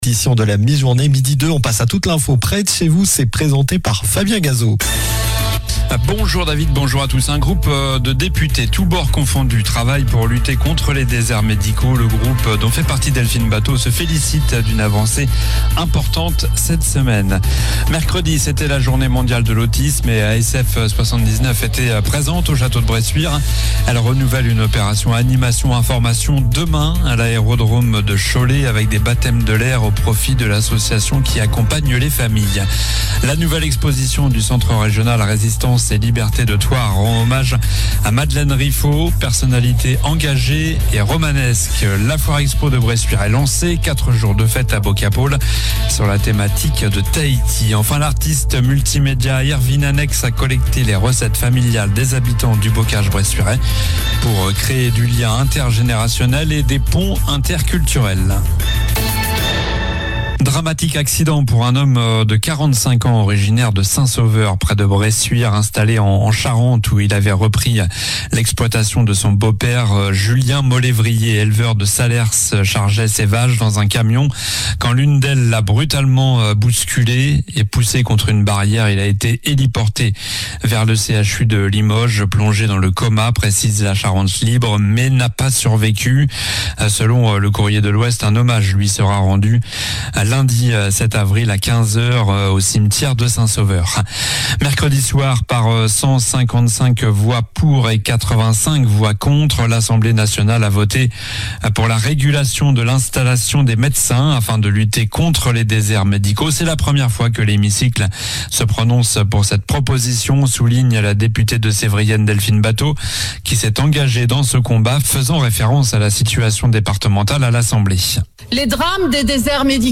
Journal du vendredi 4 avril (midi)